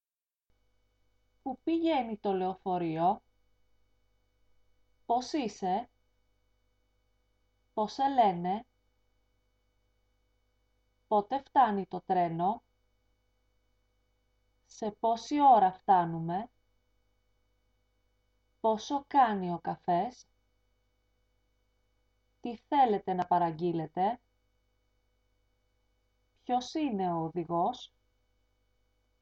lyssnaUTTAL.mp3